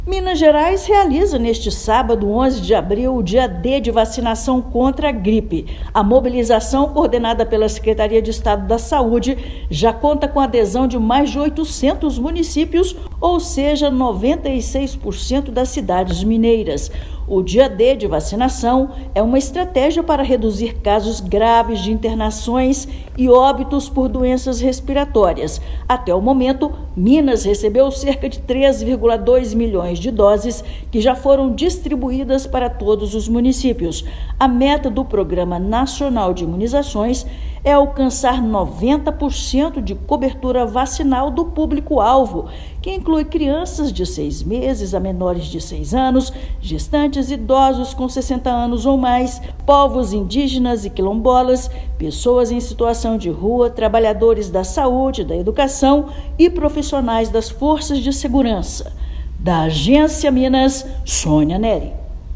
Com mais de R$ 530 milhões investidos desde 2023, Estado amplia campanhas, insumos e leva vacinação para todo o território. Ouça matéria de rádio.